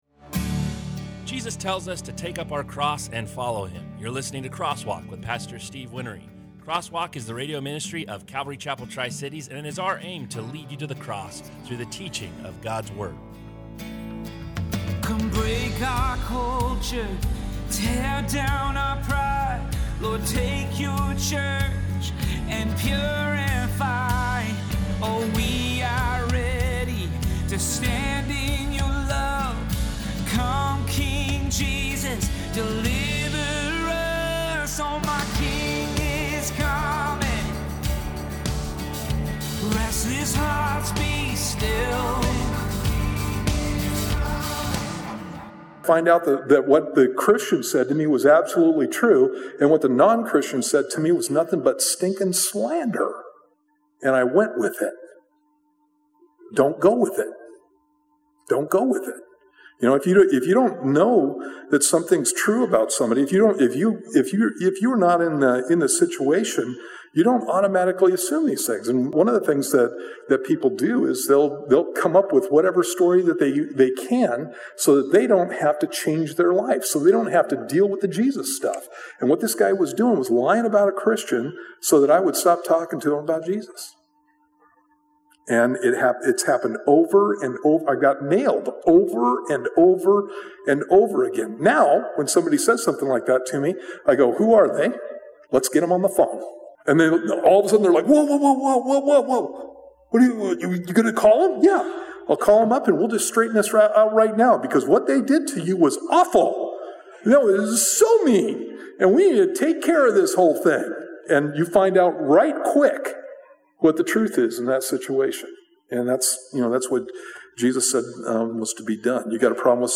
Crosswalk is a verse by verse bible study.